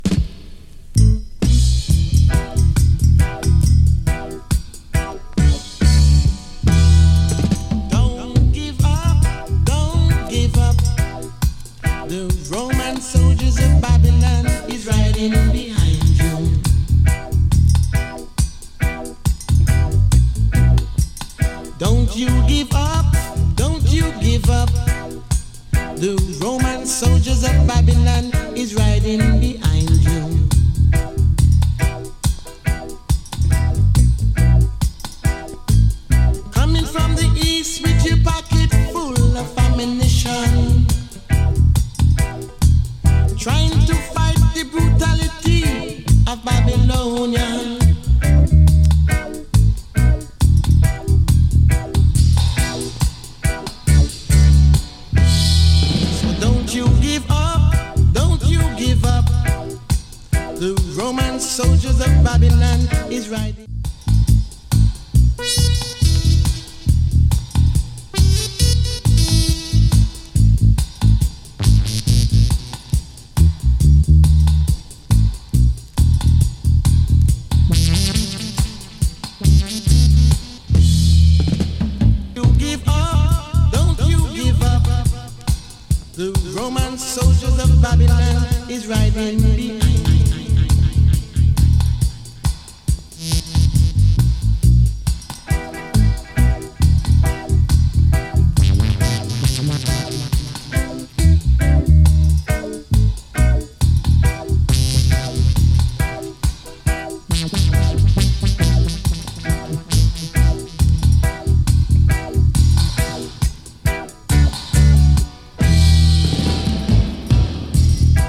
play vocal + dub